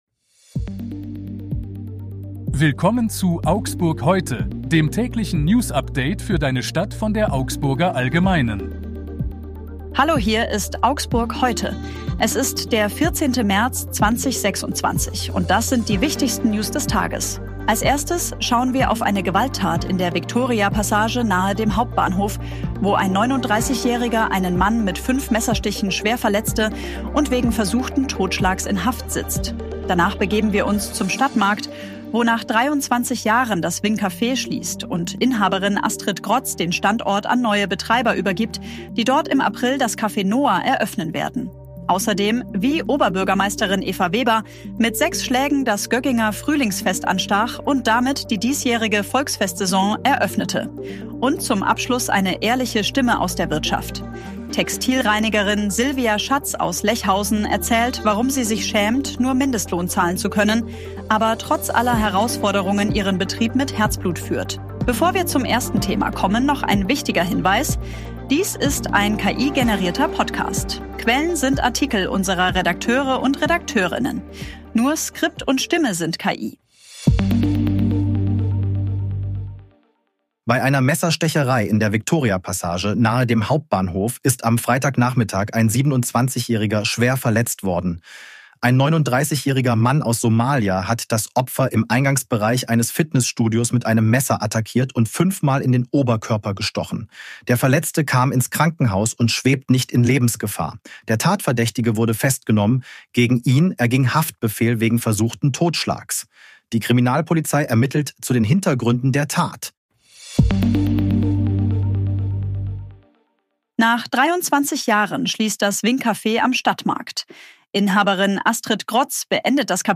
Hier ist das tägliche Newsupdate für deine Stadt.
Nur Skript und Stimme sind KI.